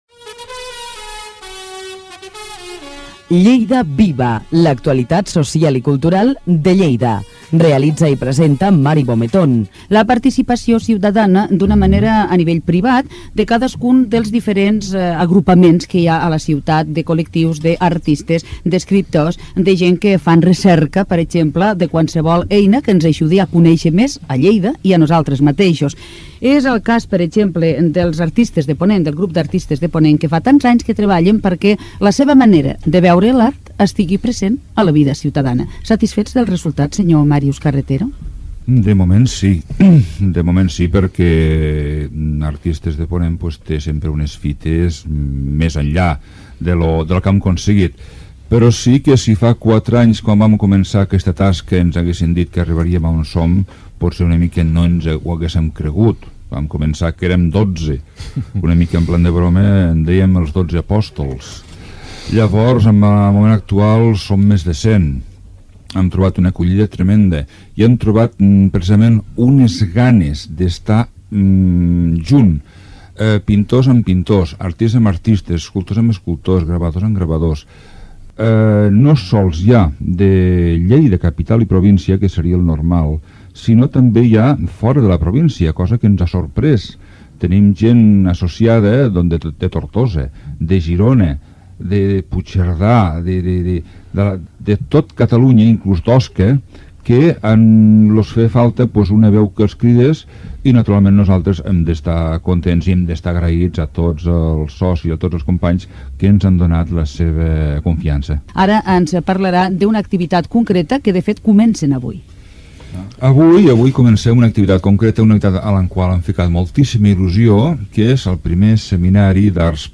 Audios: arxius sonors d’emissores diverses